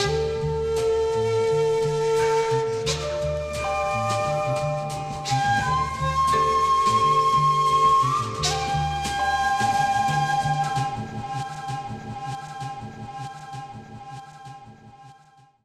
Japanese Jazz Flute Progression.wav